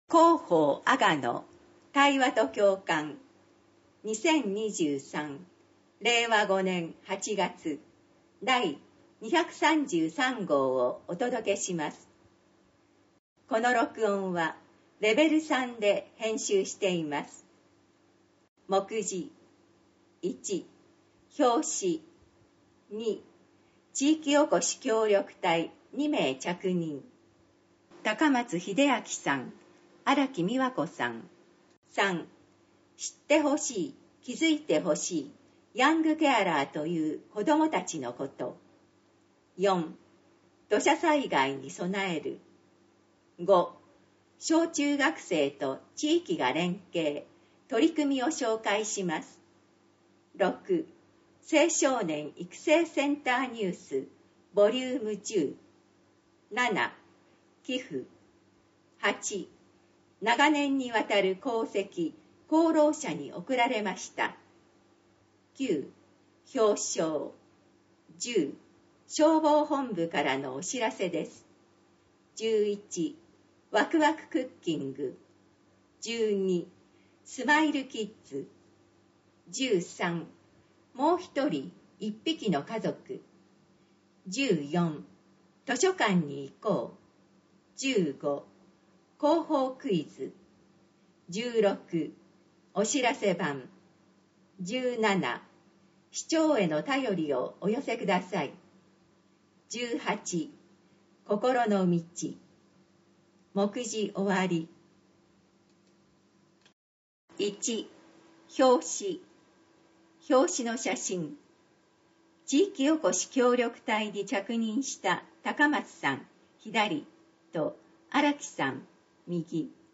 市では、視覚に障がいのある方向けに、ボランティア団体「うぐいす会」の皆さんのご協力により、広報あがのを音声訳したCDを作成し、希望する方に配付しています。